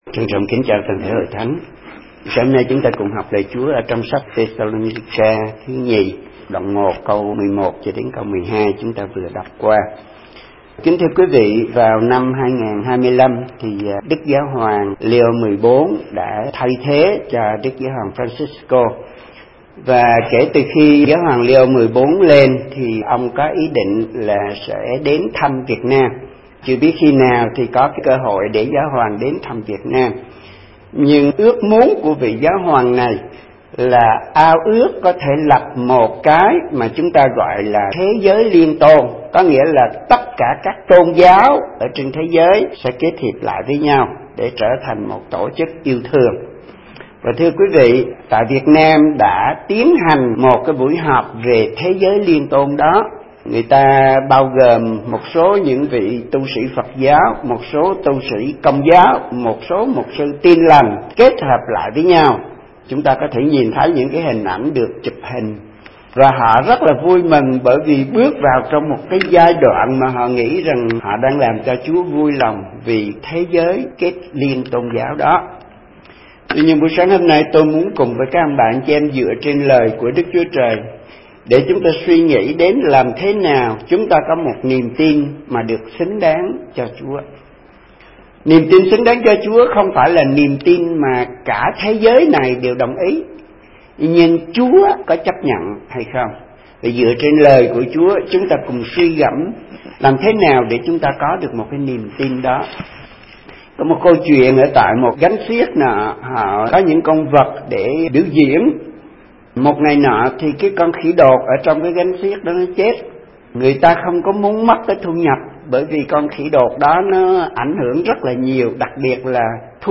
Thờ Phượng Chúa Nhật Ngày 1 Tháng 2, 2026: Được Xứng Đáng Cho Chúa